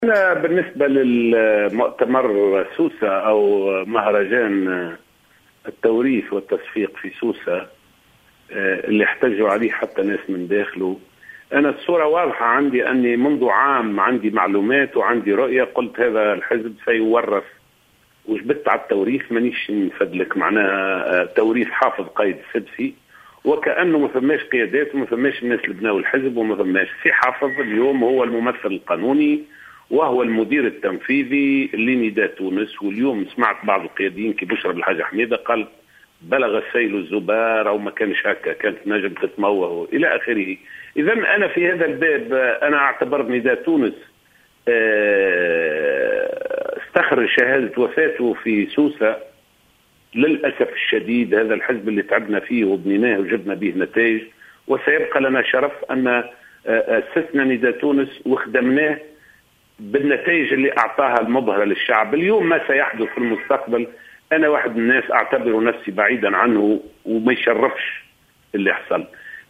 وقال العكرمي في تصريح خص به "الجوهرة أف أم" اليوم الاثنين إن المؤتمر كان بمثابة "الكرنفال" الذي كان الهدف من ورائه توريث حافظ قائد السبسي"،مضيفا أن الحزب استخرج شهادة وفاته في مؤتمر سوسة، وفق تعبيره.